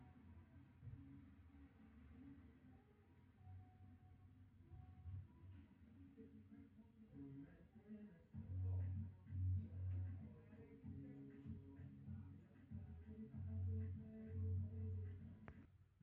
Załączam oba nagrania z telefonu, prosibłym aby ktoś pomógł mi to rozpoznać.:) Dodam tylko że trzeba podgłośnić na urządzeniu bo jest sporo ciche.